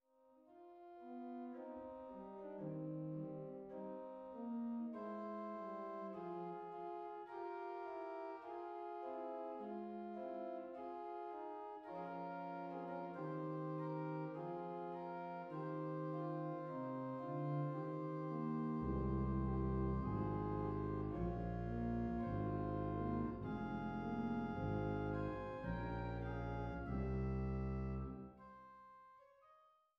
Weise-Orgel in Gräfenroda